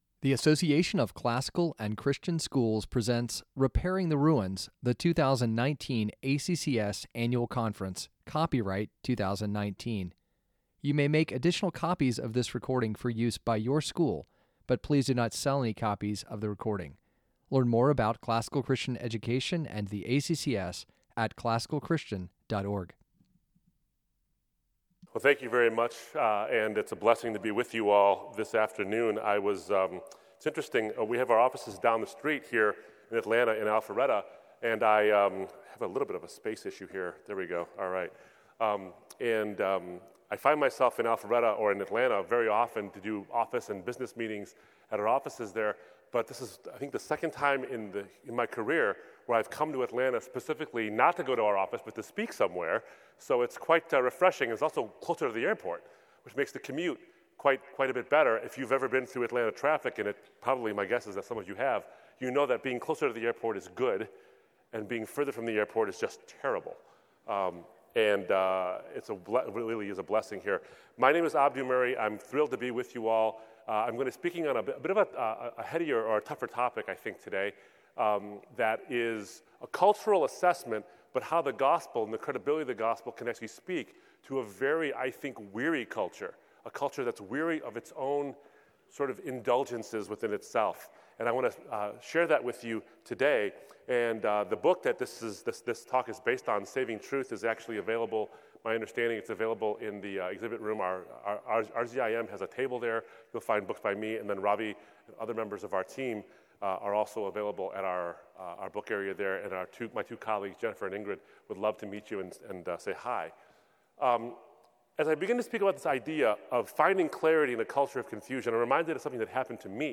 2019 Plenary Talk | 51:34 | All Grade Levels, Culture & Faith